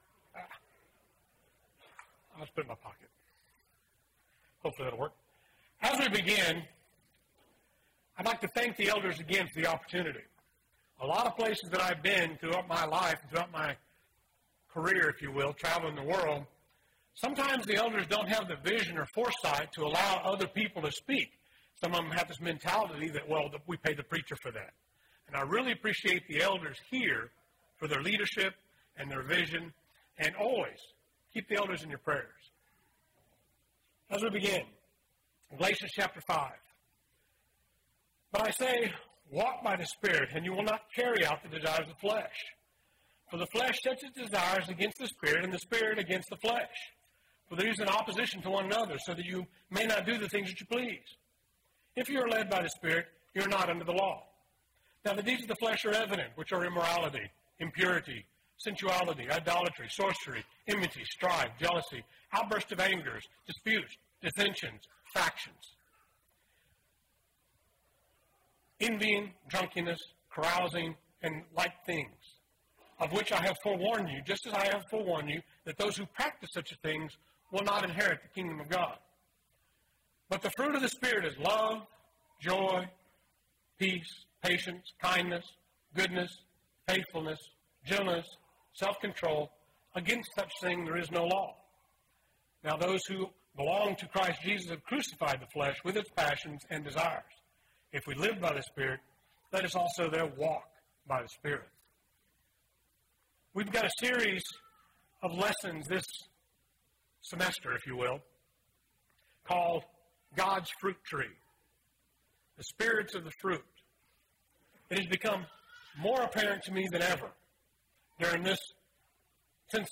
Wednesday PM Bible Class